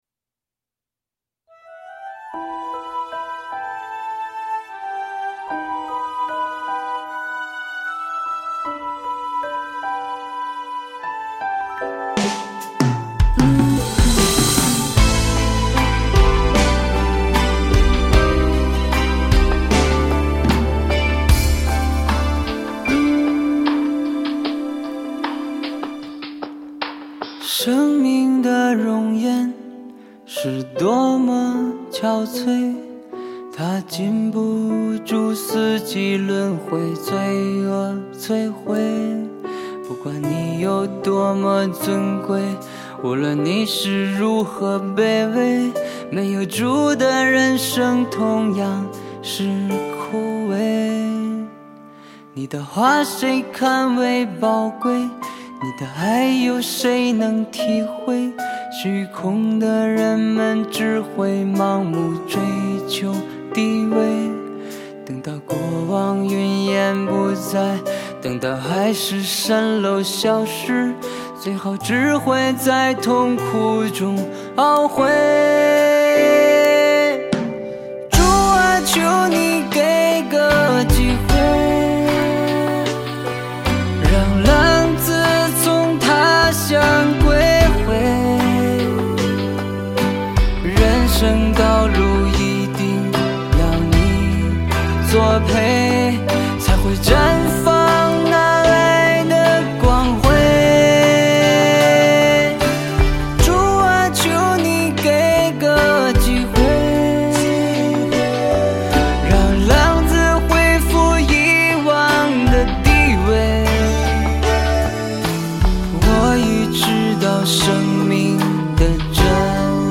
HAKA祷告敬拜MP3 启示性祷告： 持续祷告： 祈求神的旨意成就在我们身上，启示性恩膏临到，明白身份，听到呼召，进入命定！